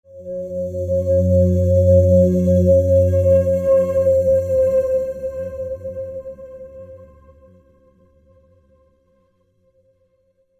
不気味な効果音。
ホラー効果音 着信音